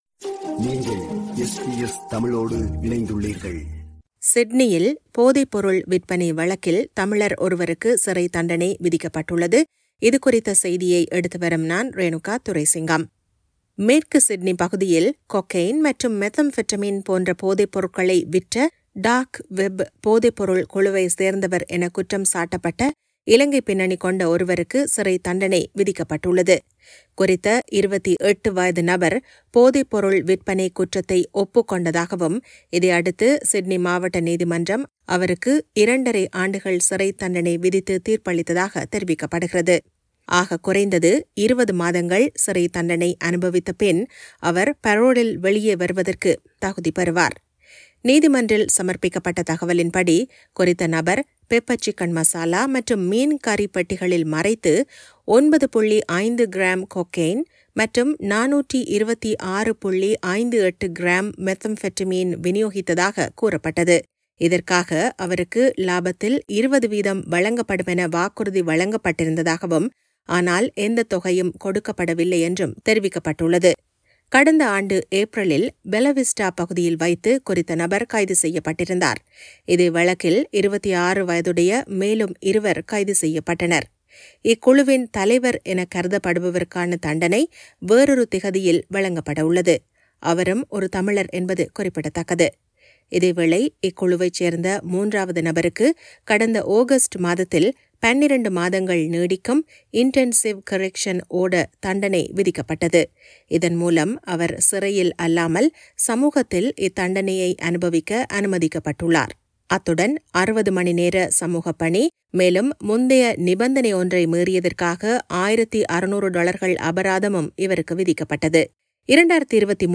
சிட்னியில் போதைப்பொருள் விற்பனை வழக்கில் தமிழர் ஒருவருக்கு சிறைத்தண்டனை விதிக்கப்பட்டுள்ளது. இதுகுறித்த செய்தியை எடுத்துவருகிறார்